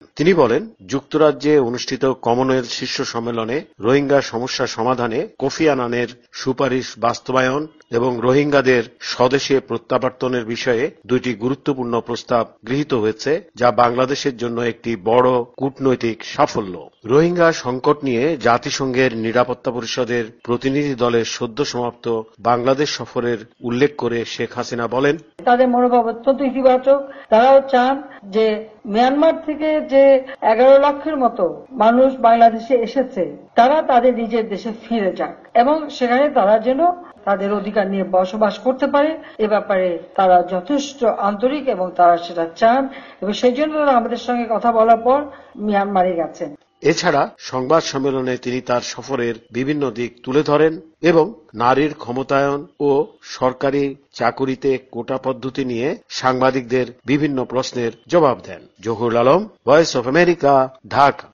ঢাকা থেকে প্রধানমন্ত্রী হাসিনার বক্তব্য নিয়ে প্রতিবেদন